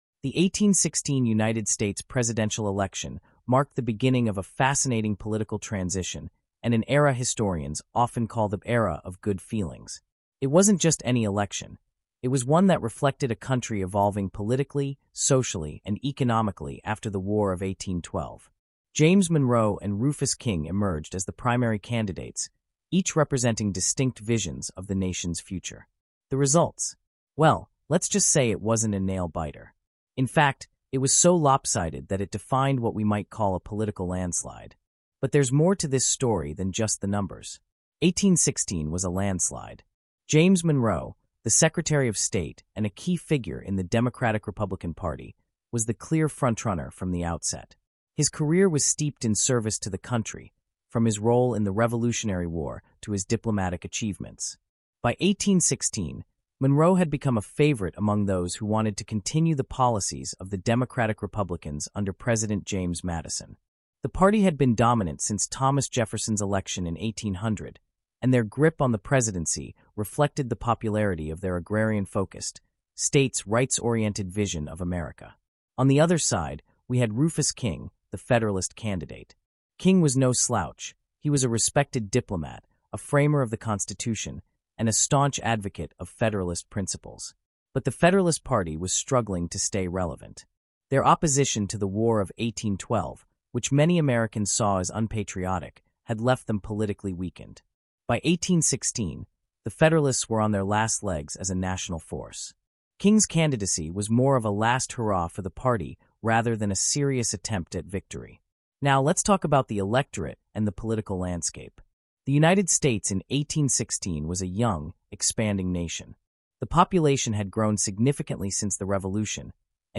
Disclosure: This podcast includes content generated using an AI voice model. While efforts were made to ensure accuracy and clarity, some voices may not represent real individuals.